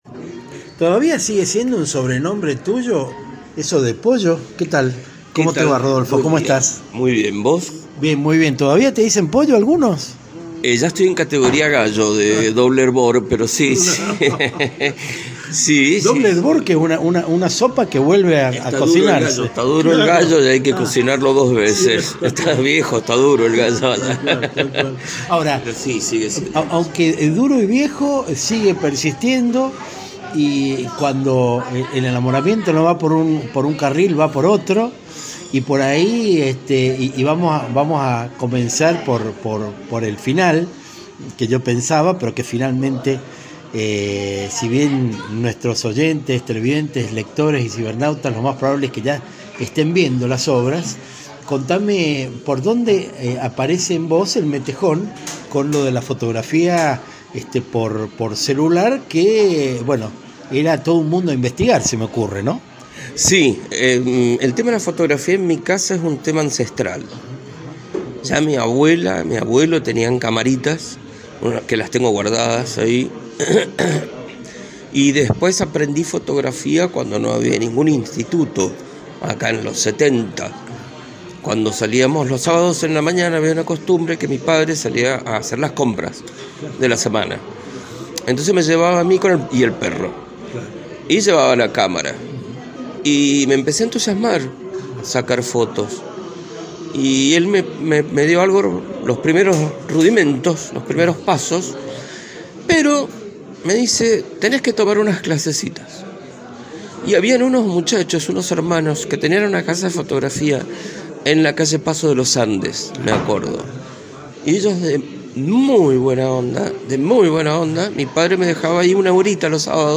Entrevistas Latinocracia